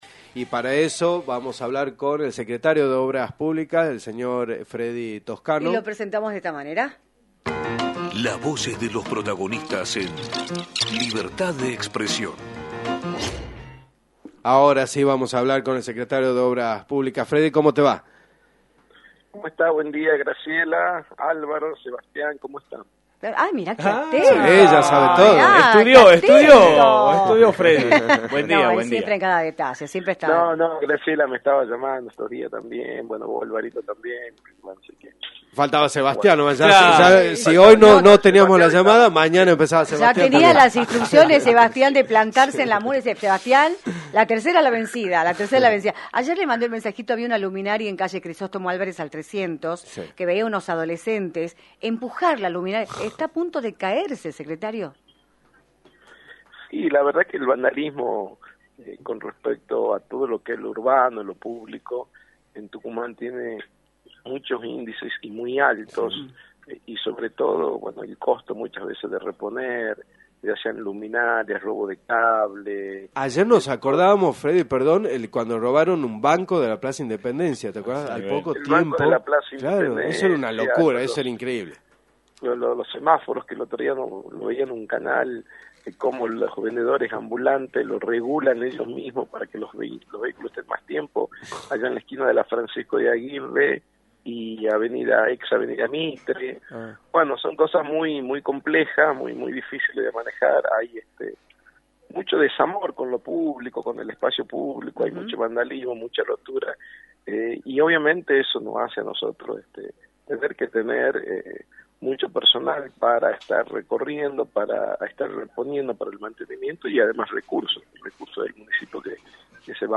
Fredy Toscano, Secretario de Obras Públicas de la Municipalidad de San Miguel de Tucumán y Legislador electo por Juntos por el Cambio, analizó en “Libertad de Expresión”, por la 106.9, la situación de la Municipalidad en materia de obras públicas y el resultado de las elecciones provinciales.